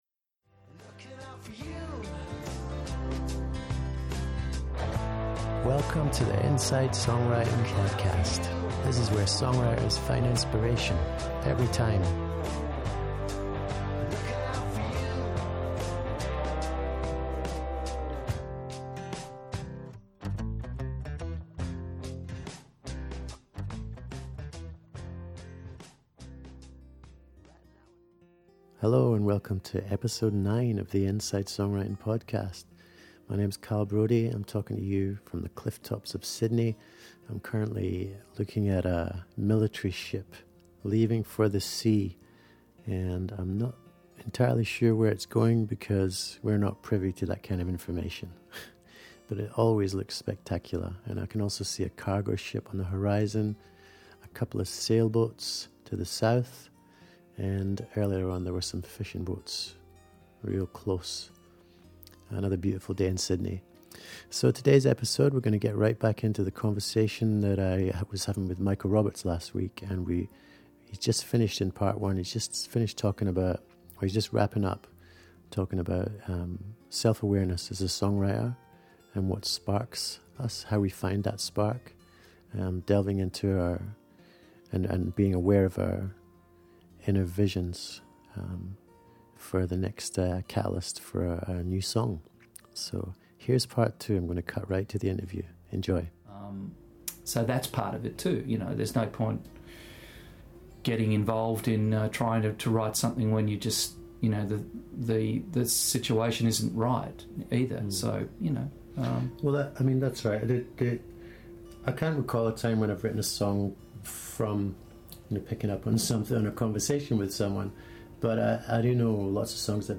As a long-time friend and mentor, we somehow even fit in some real-time songwriting tips and mentorship into this conversation.